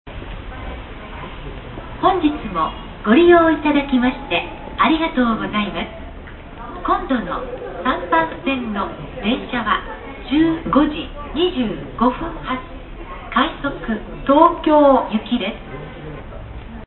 次発放送次発放送です。